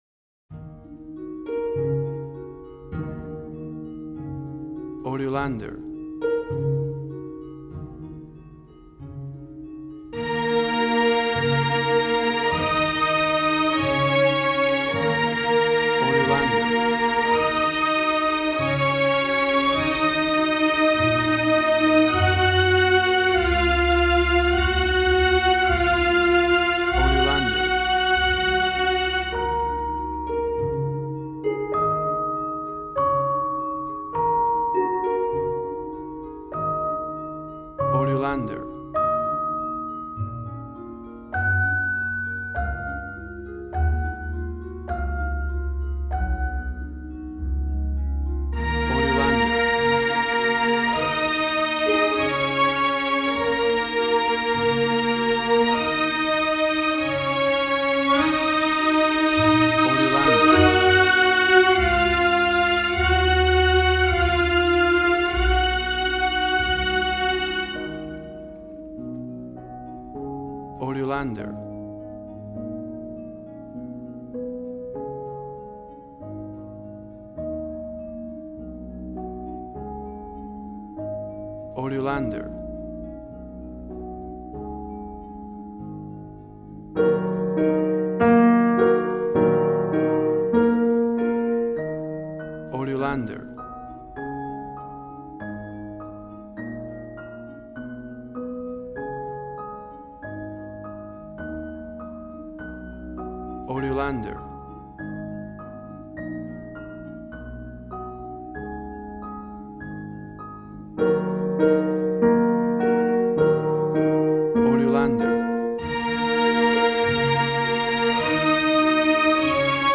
Tempo (BPM): 50